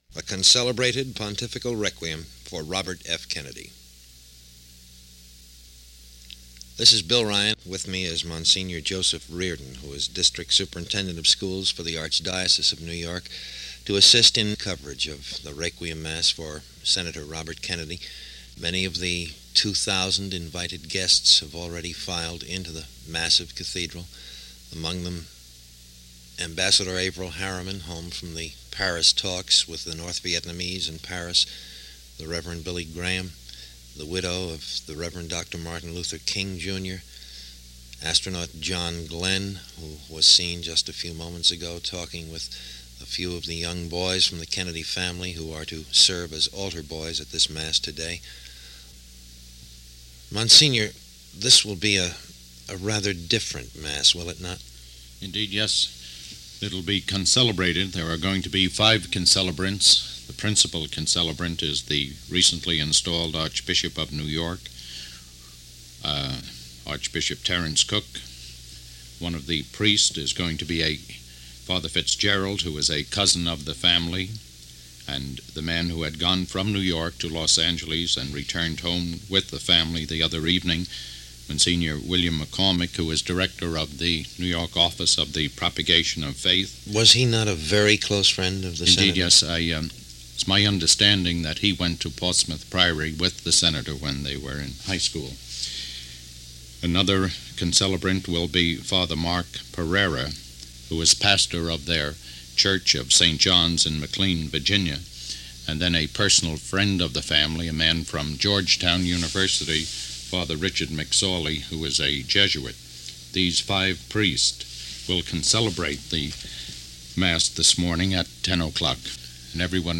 June 8, 1968 – Funeral Service For Robert F. Kennedy – NBC Radio continuous Coverage